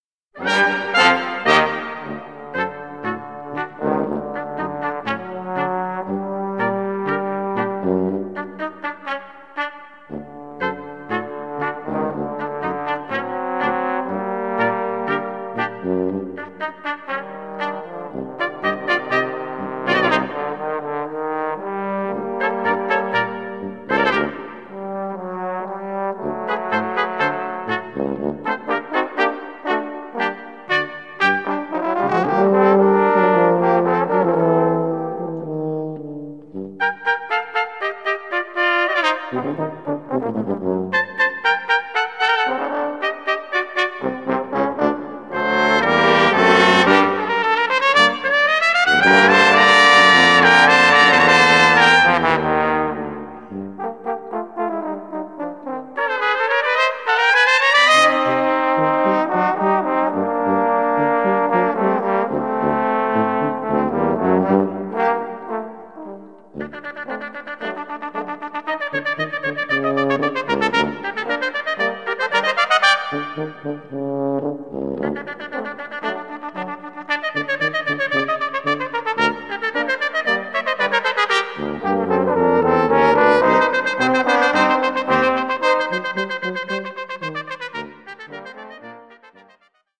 Das "etwas andere" Bläserquintett!